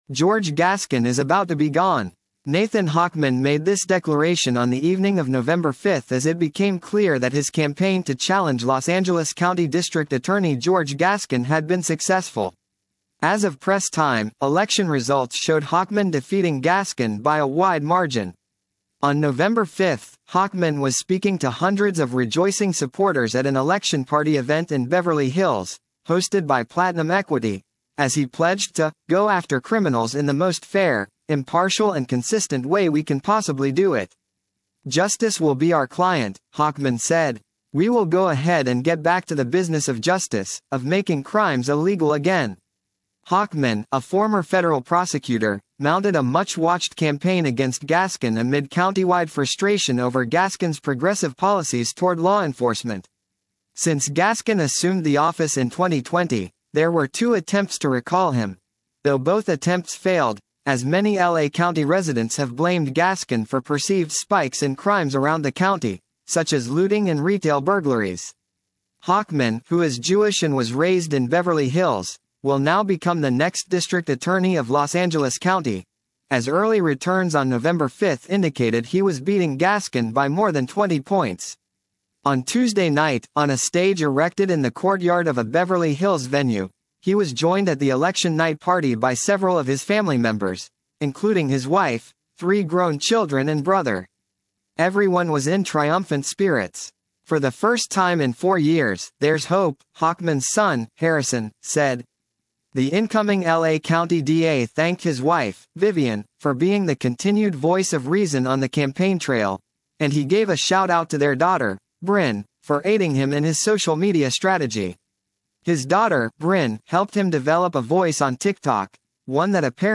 Incoming L.A. County District Attorney Nathan Hochman (center) speaks to supporters during an election night party in Beverly Hills.
On Nov. 5, Hochman was speaking to hundreds of rejoicing supporters at an election party event in Beverly Hills, hosted by Platinum Equity, as he pledged to “go after criminals in the most fair, impartial and consistent way we can possibly do it.”